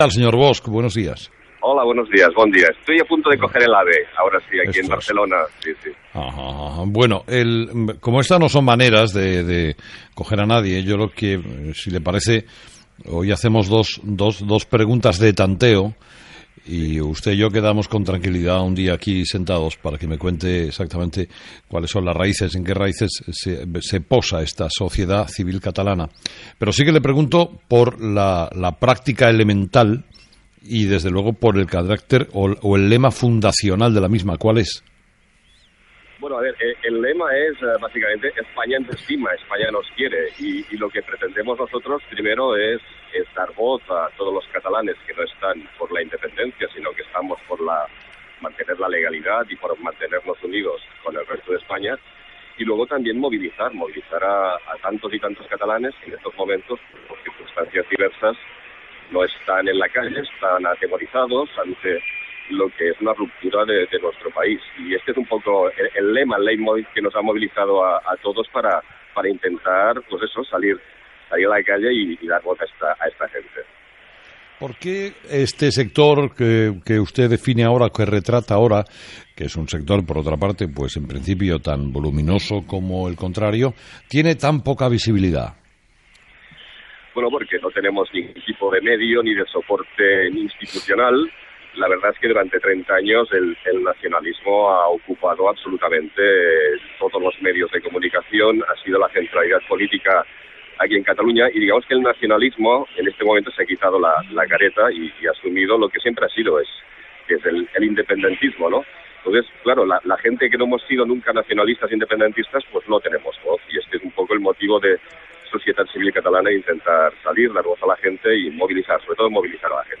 Últimas Noticias/Entrevistas